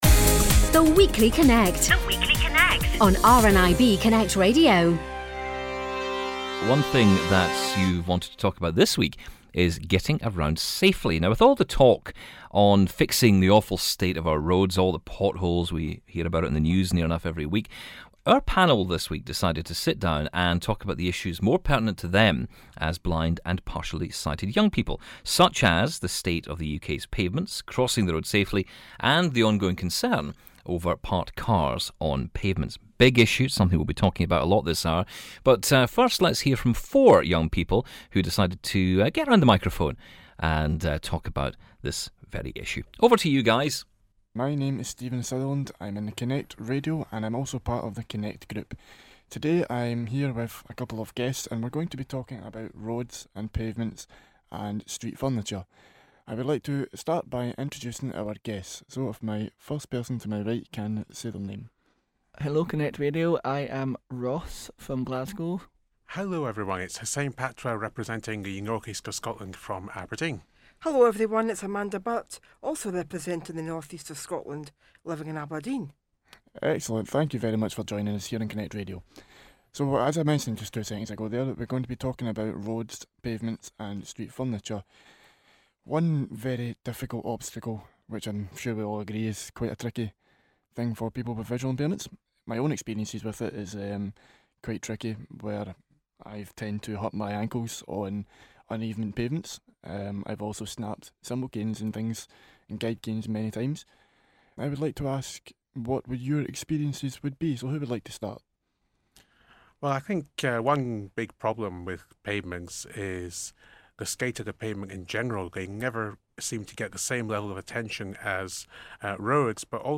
Four blind and partially sighted people sat down to discuss how they get around their respective towns and villages despite their sight problems.